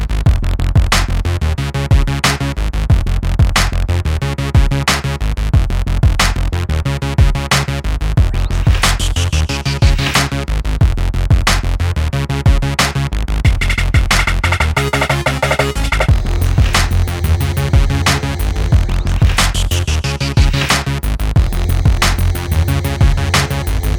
no Backing Vocals R'n'B / Hip Hop 4:29 Buy £1.50